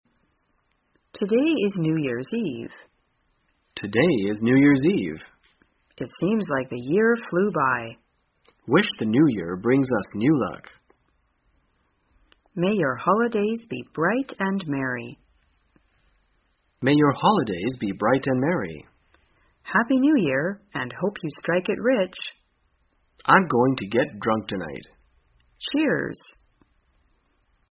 在线英语听力室生活口语天天说 第253期:怎样祝贺新年的听力文件下载,《生活口语天天说》栏目将日常生活中最常用到的口语句型进行收集和重点讲解。真人发音配字幕帮助英语爱好者们练习听力并进行口语跟读。